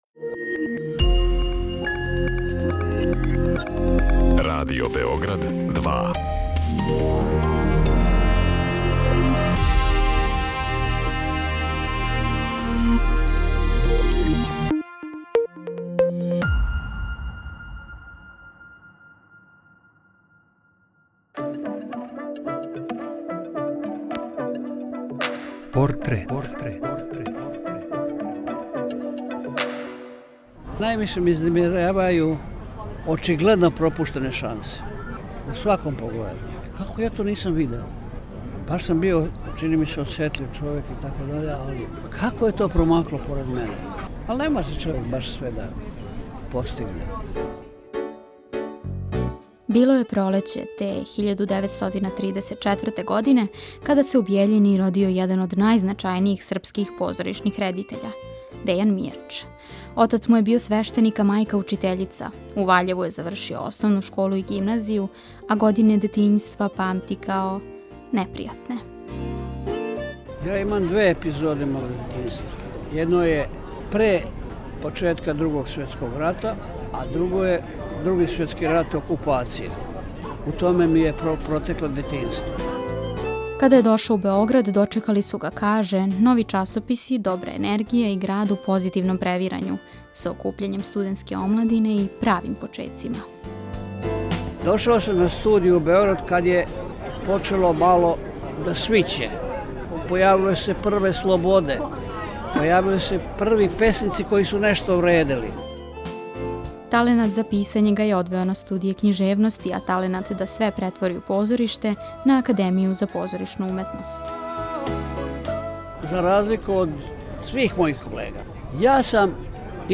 Приче о ствараоцима, њиховим животима и делима испричане у новом креативном концепту, суптилним радиофонским ткањем сачињеним од: интервјуа, изјава, анкета и документраног материјала.
Осим Мијача, чућете његове пријатеље и сараднике - глумце Воју Брајовића и Ирфана Менсура, као и академика, драмског писца, песника и есејисту Љубомира Симовића.